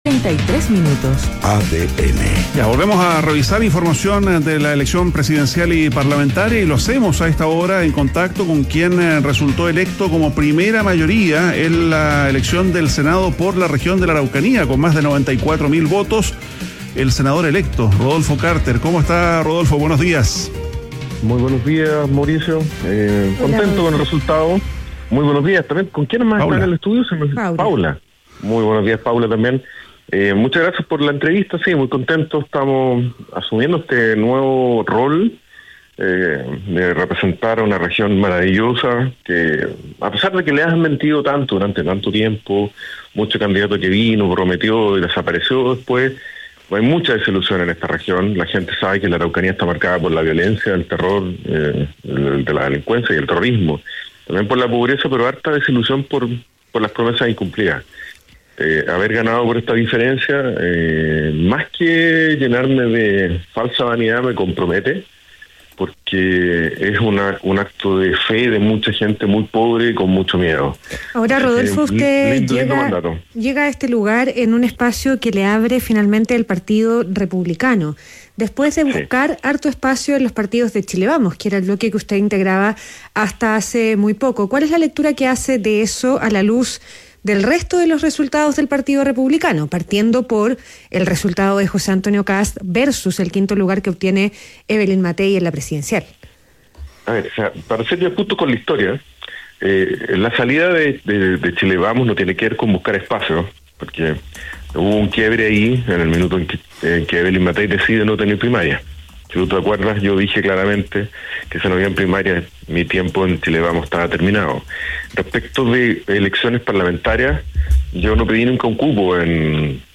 ADN Hoy - Entrevista a Rodolfo Carter, senador electo y exalcalde de La Florida